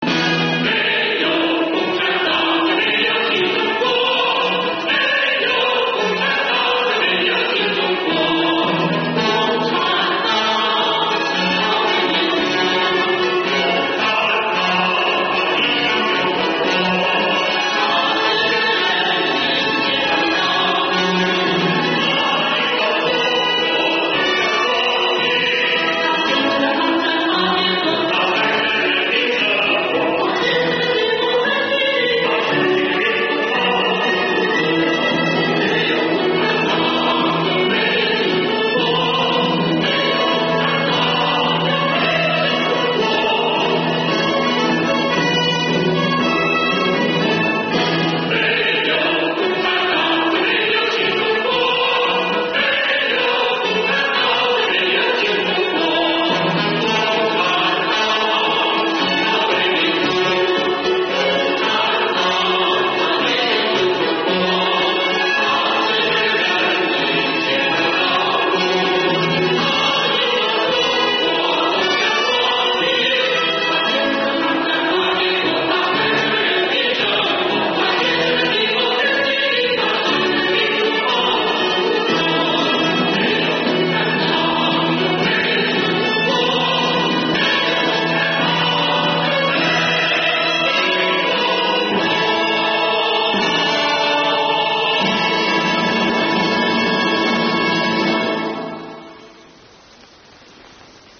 灵山县税务局歌伴舞《灯火里的中国》